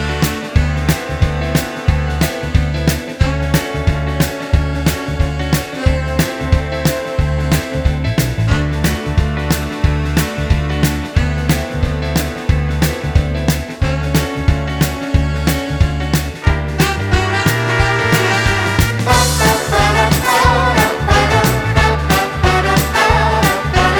no Backing Vocals Indie